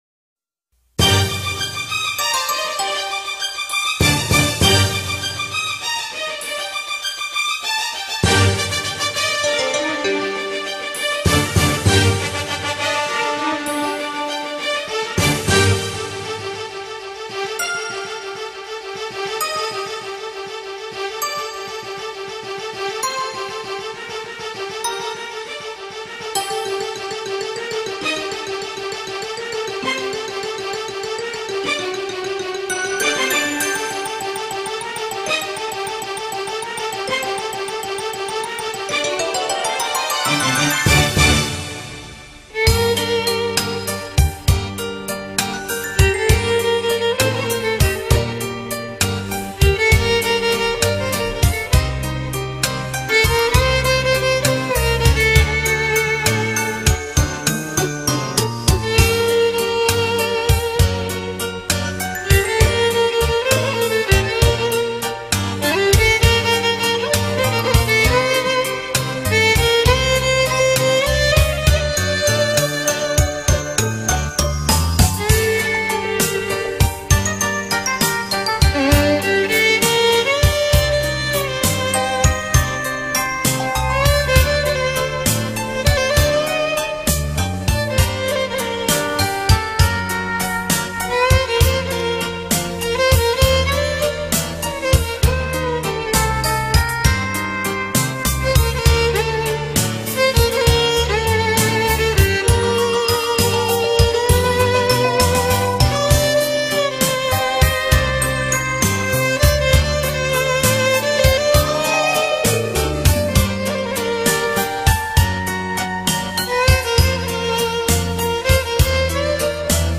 아르메니아 클래식 바이올린 리스트